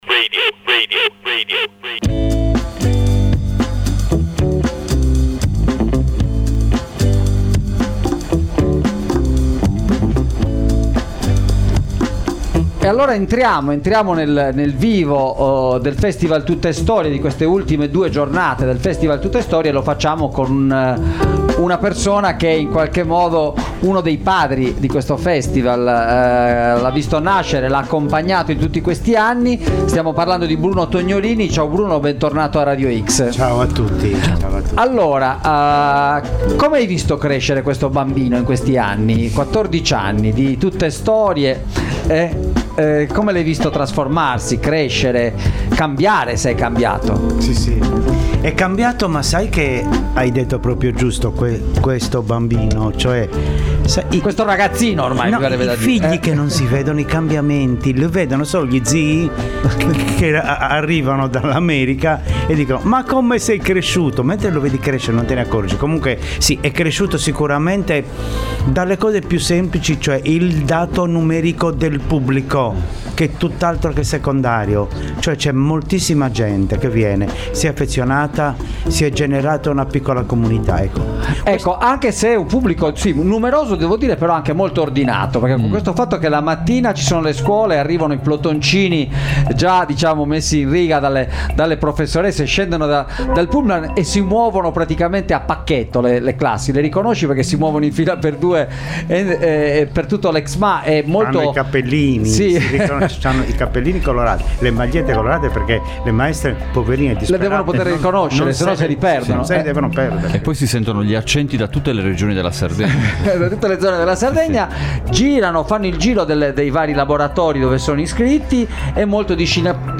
In diretta dal festival, quattro chiacchiere con Bruno Tognolini, “papà” di Tuttestorie, per ripercorrere i 14 anni della rassegna che ogni anno coinvolge migliaia di ragazzi in arrivo da tutta l’isola e per svelarci in anteprima i segreti della serata finale del festival che quest’anno si chiuderà con un rito dedicato alla nostra grande madre.